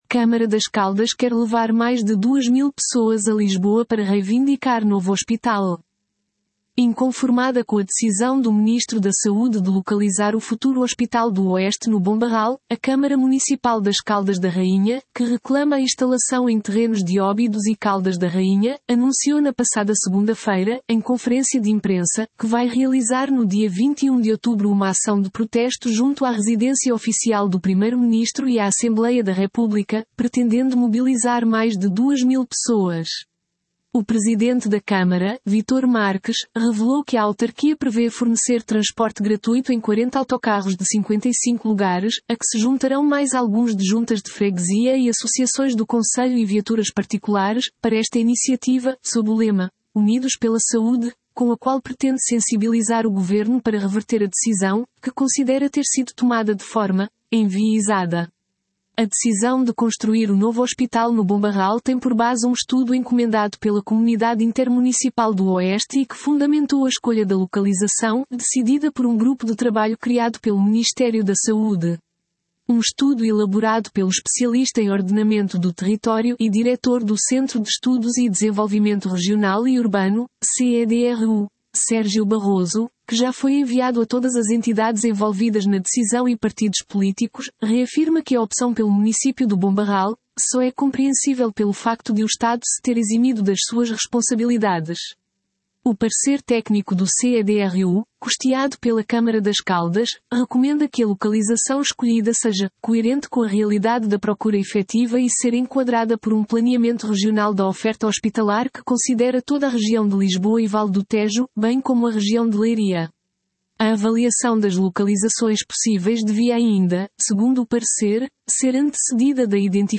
Conferência de imprensa nos Paços do Concelho para anunciar a ação de protesto